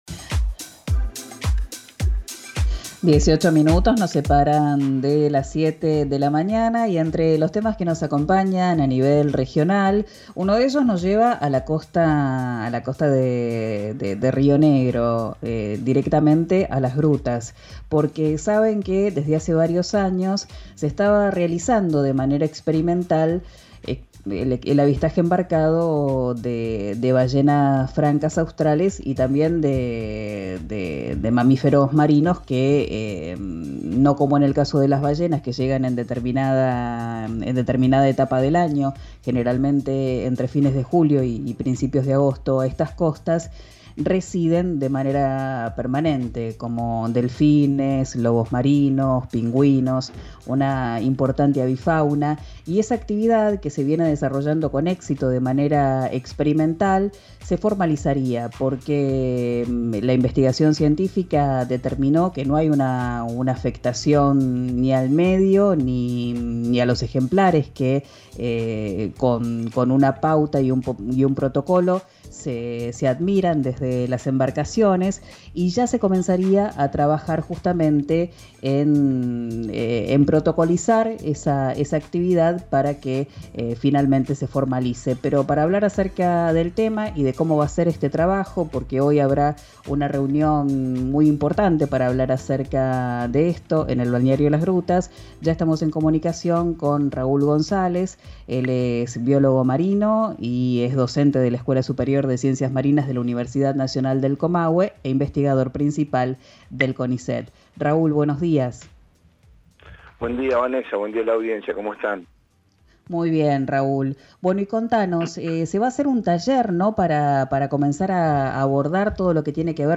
en diálogo con RÍO NEGRO RADIO .